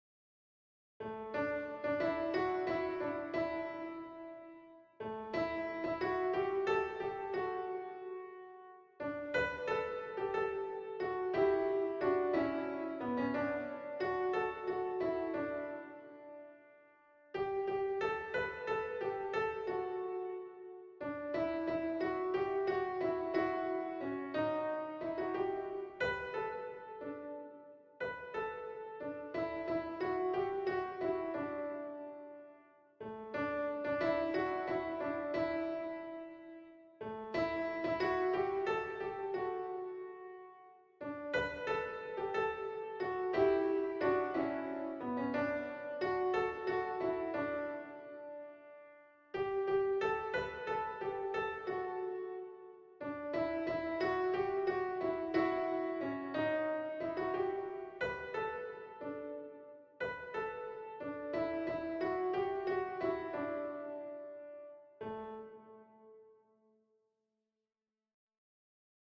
und die Melodie zum Erlernen der Singstimme als MP3.